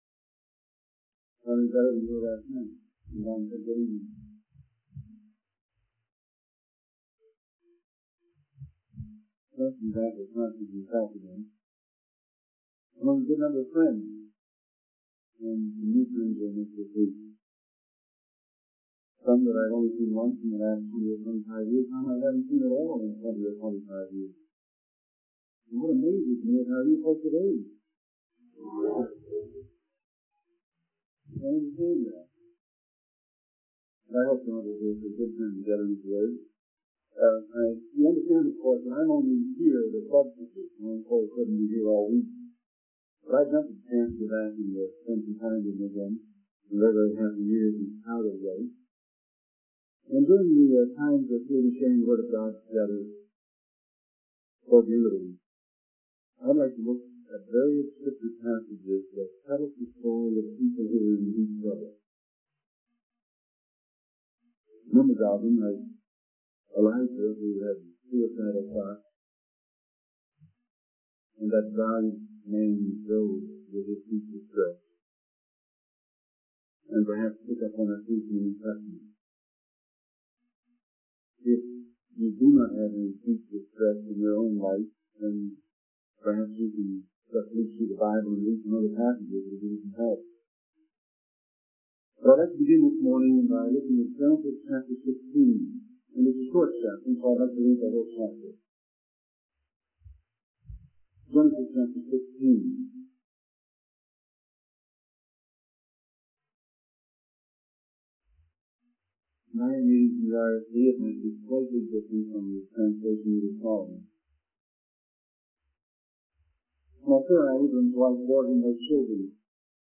In this sermon, the speaker emphasizes that Israel was not God's ultimate goal, but rather a means for God to reach the world. The speaker highlights the importance of having a ministry to the poor and marginalized, as this reflects the character of God.